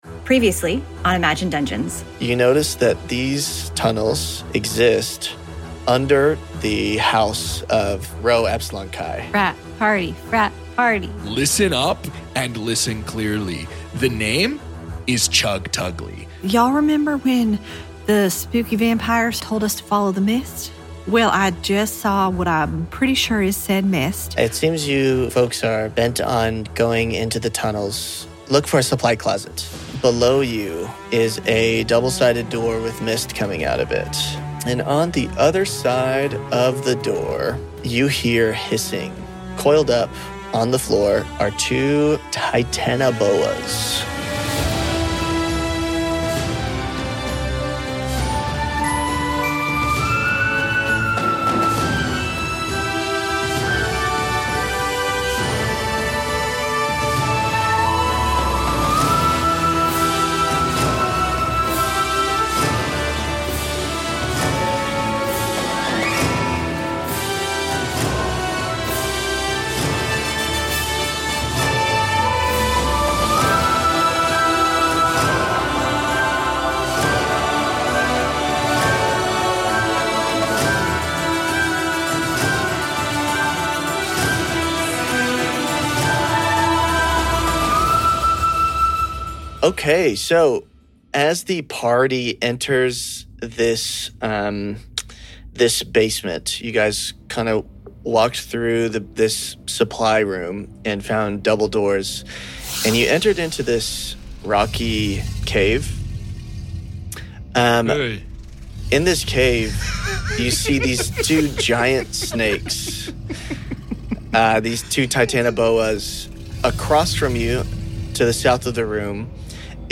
Imagine Dungeons An Actual-Play D&D Podcast Author: Imagine Dungeons Real friends.